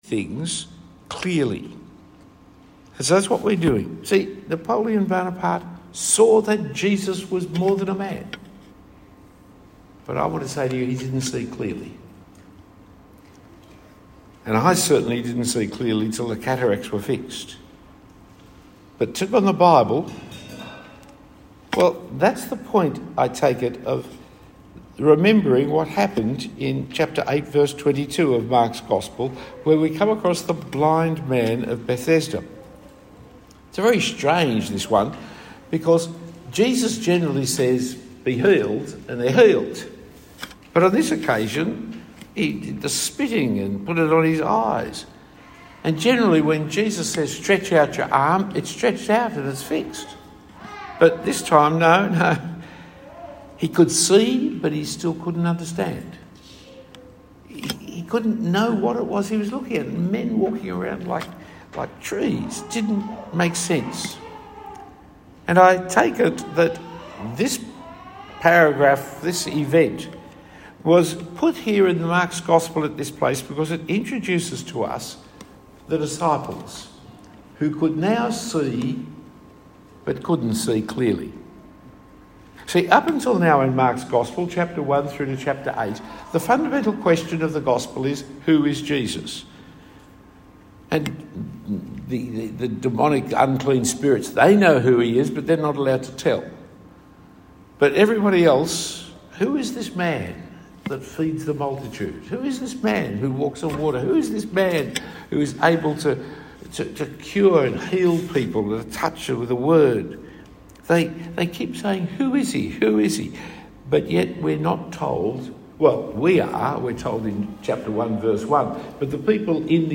Talk 1 of 4 given at the South Coast MTS Mission Minded Conference.